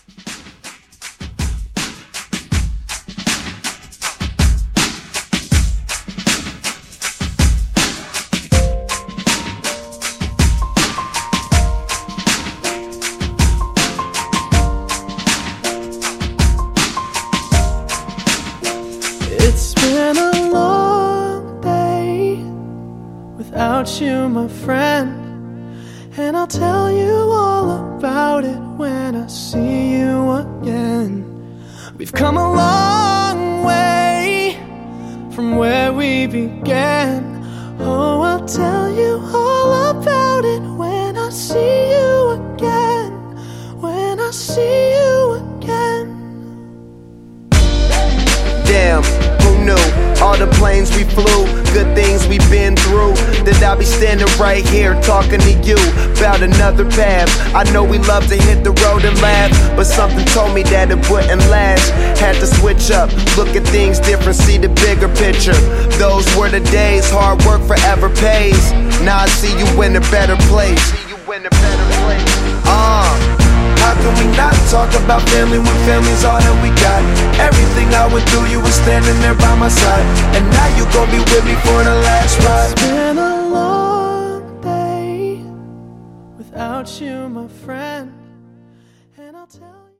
Genre: DANCE
Clean BPM: 80 Time